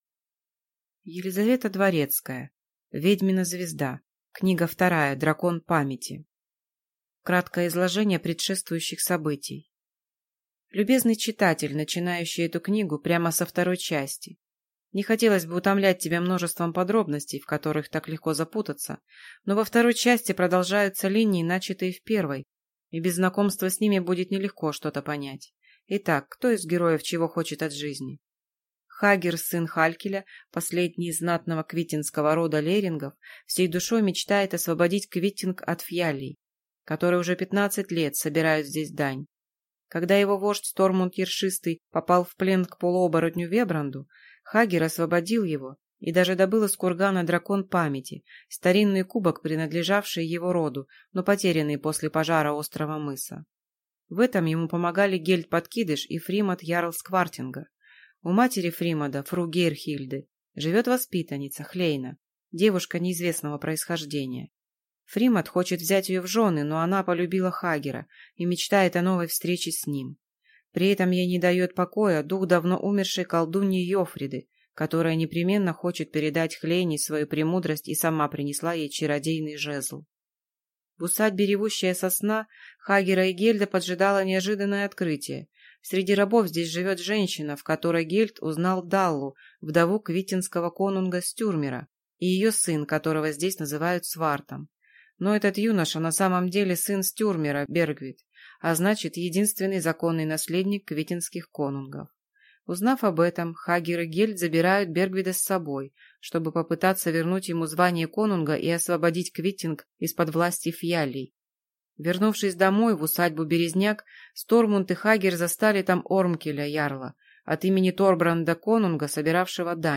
Аудиокнига Ведьмина звезда. Книга 2: Дракон Памяти | Библиотека аудиокниг
Прослушать и бесплатно скачать фрагмент аудиокниги